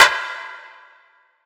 DDW4 CLAP 1.wav